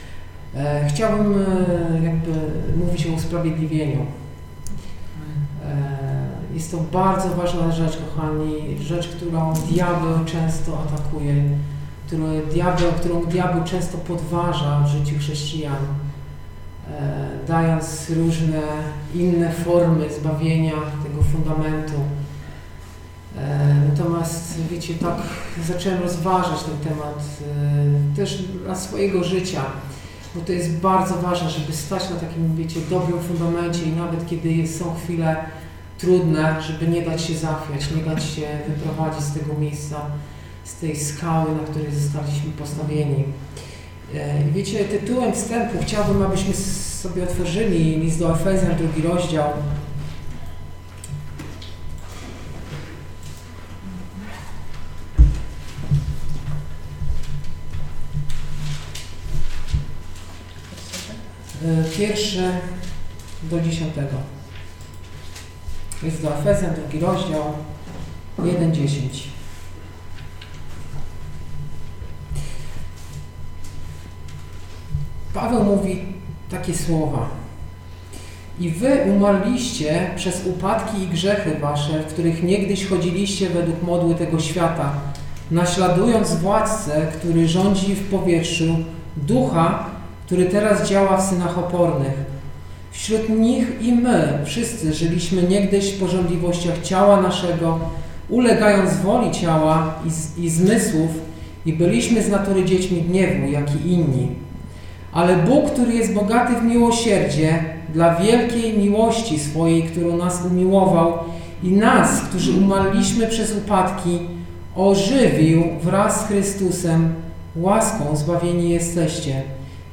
Posłuchaj kazań wygłoszonych w Zborze Słowo Życia w Olsztynie